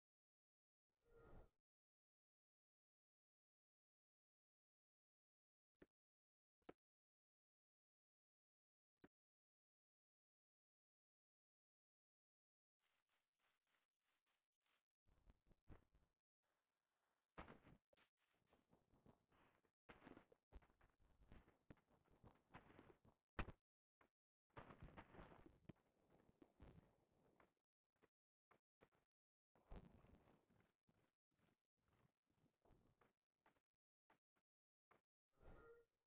All sounds have a weird high and low pitch
Everything sounds like it's 8-bit. Maybe like all sounds do not have middle only bass and high pitches.